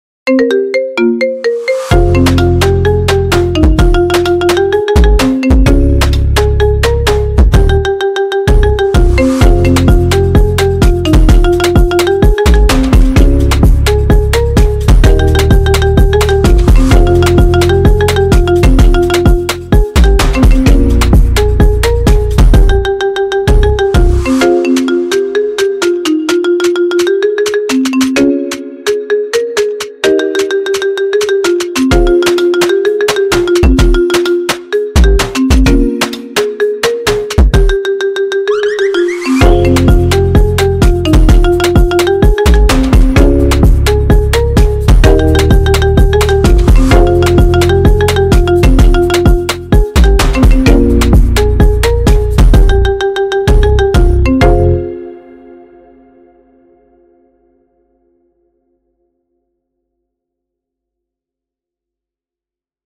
Kategoria Marimba Remix